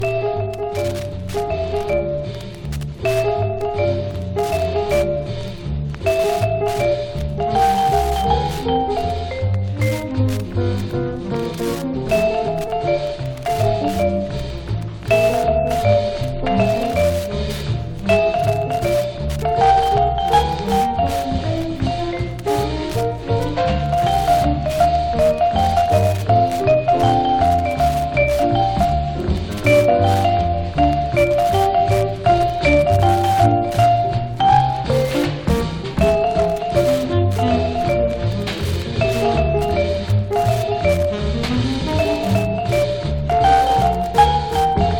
Jazz, Swing　USA　12inchレコード　33rpm　Mono
ジャケスレ汚れ　盤スレキズ　盤の材質によるノイズ有